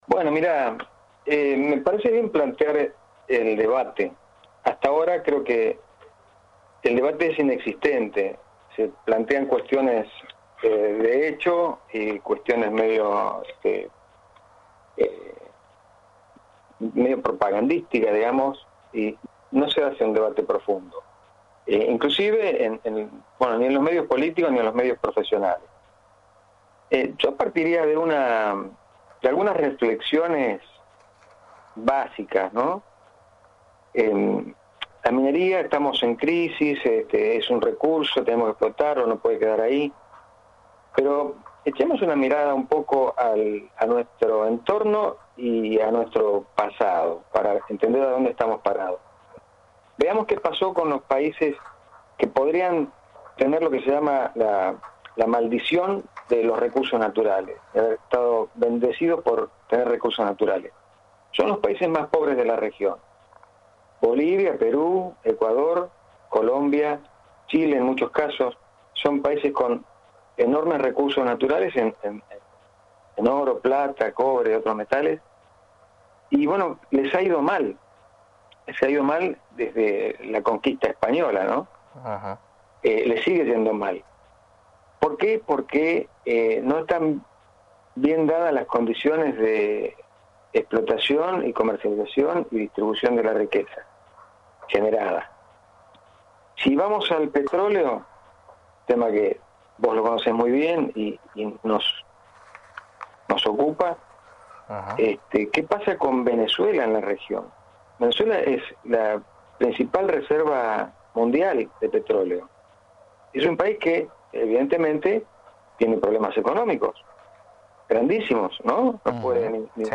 mantuvo un extenso diálogo en el espacio de Actualidad 2.0 por FM del Mar